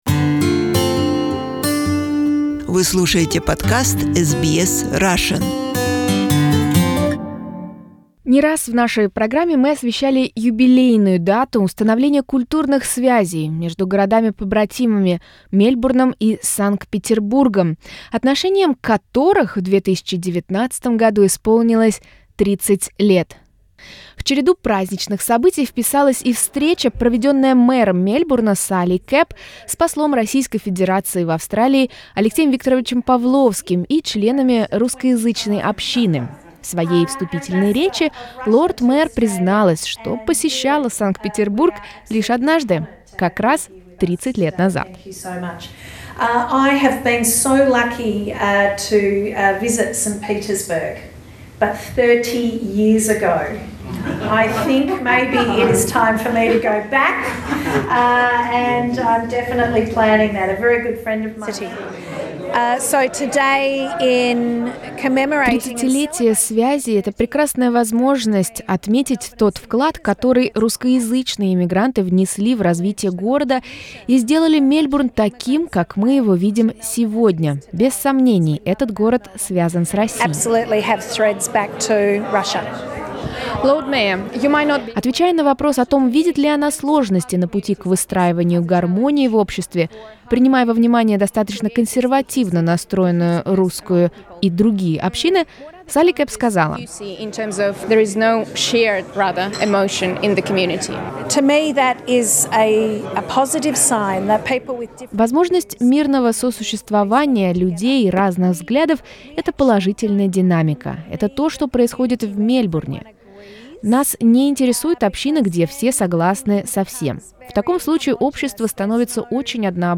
We meet with the Ambassador of the Russian Federation to the Commonwealth of Australia Alexey Pavlovski and the lord Mayor of Melbourne at the event celebrating the 30th anniversary of the cultural relationships of Sister cities St Petersburg and Melbourne.